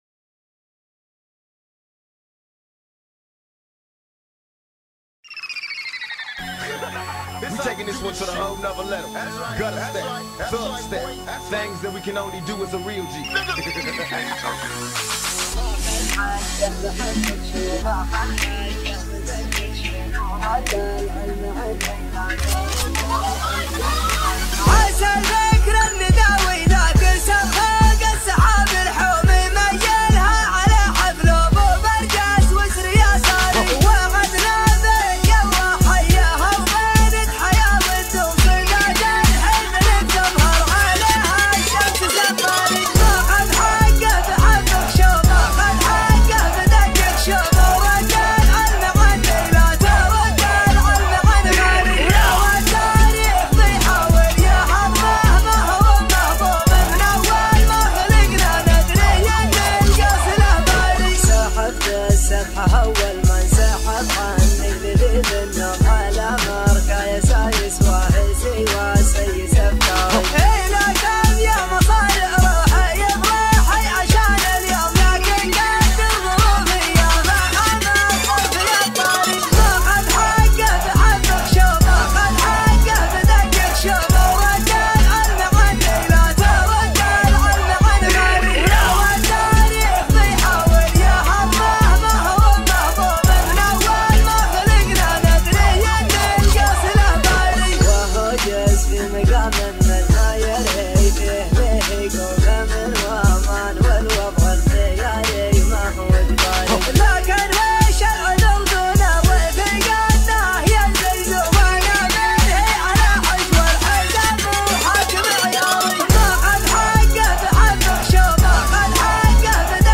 شيلات طرب / شيلات مكس